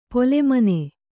♪ pole mane